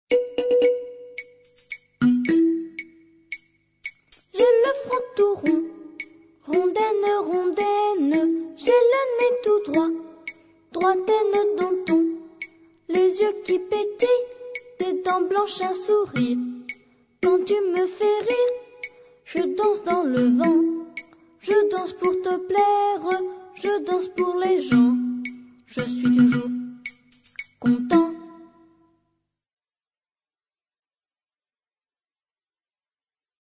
Deux années de travail, des enregistrements en studio, une dessinatrice, beaucoup d'énergie de notre part.